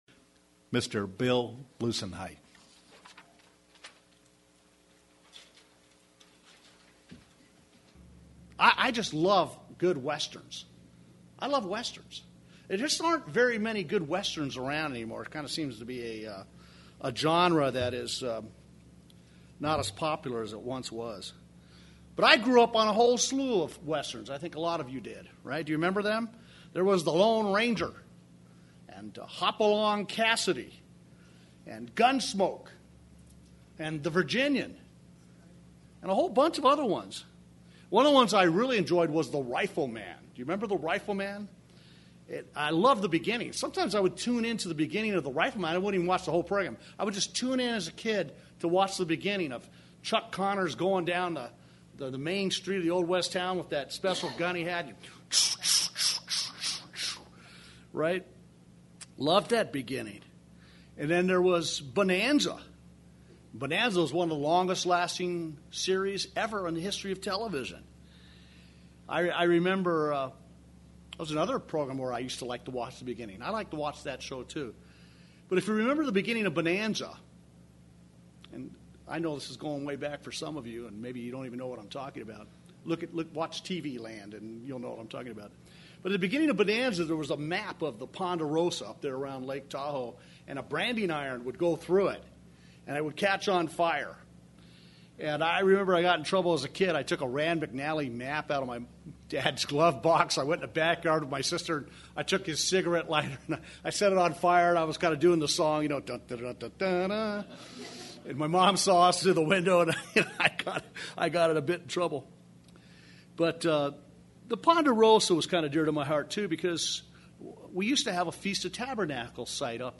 Sermons
Given in Redlands, CA